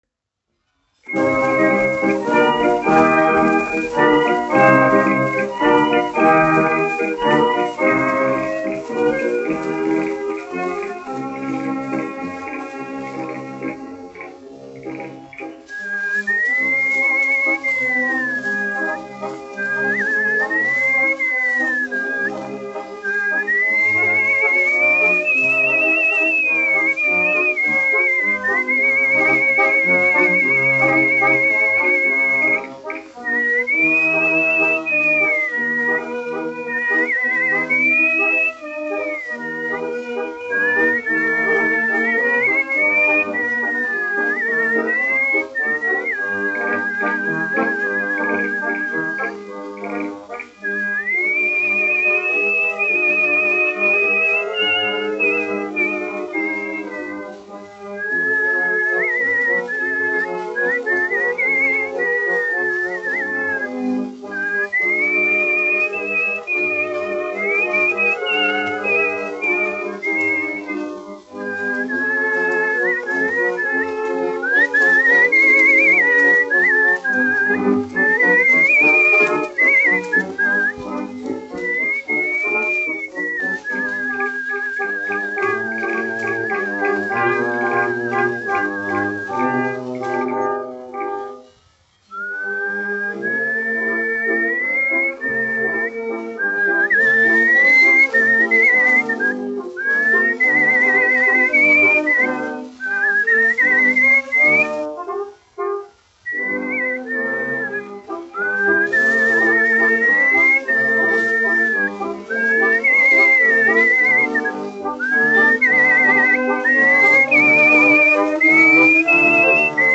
1 skpl. : analogs, 78 apgr/min, mono ; 25 cm
Orķestra mūzika, aranžējumi
Populārā instrumentālā mūzika
Skaņuplate